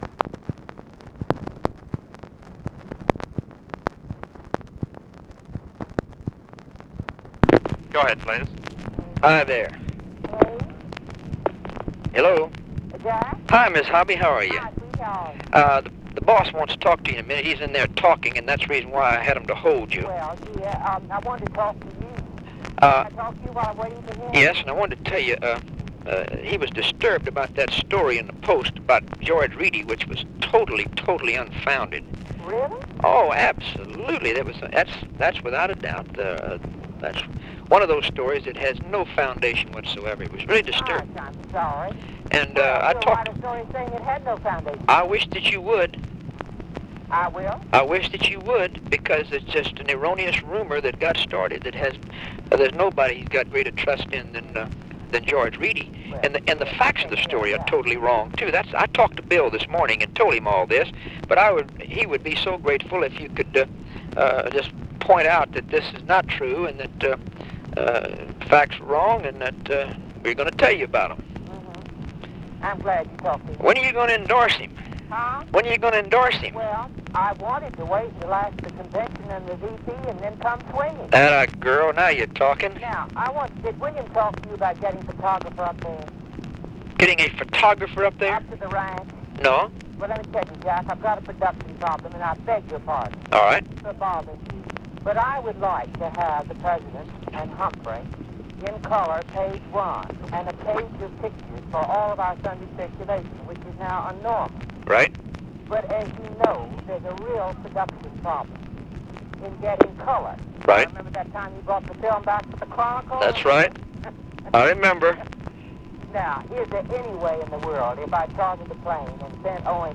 Conversation with OVETA CULP HOBBY, JACK VALENTI and HUBERT HUMPHREY, August 28, 1964
Secret White House Tapes